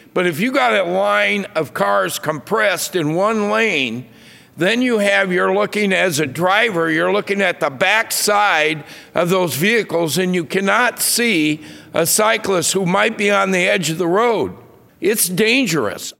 Senator William Dotzler (DOTS-ler) of Waterloo, one of the “no” votes, says it’s aggravating to have a slow moving vehicle in the left lane, but Dotzler says he’s concerned about the safety of bicyclists riding along the side of city streets that have two lanes of traffic in both directions.